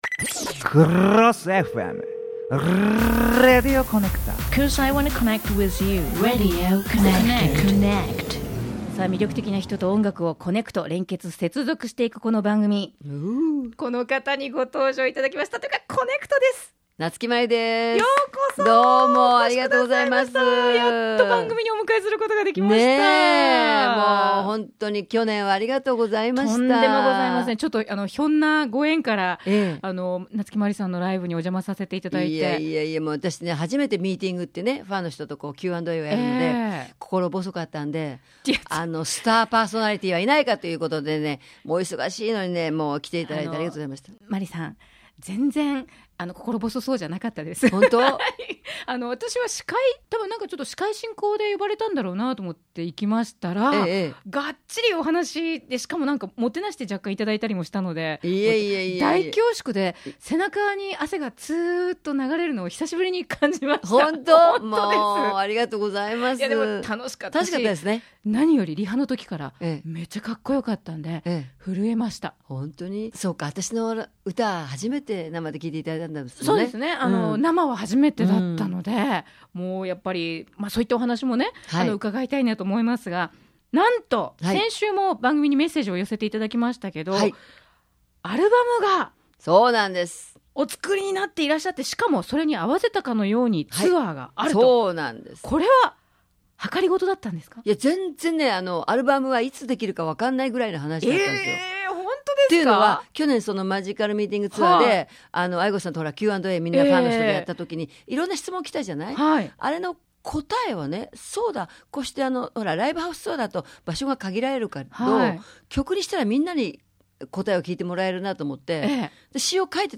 先週の”一問一答”編から〜今夜はインタビュー！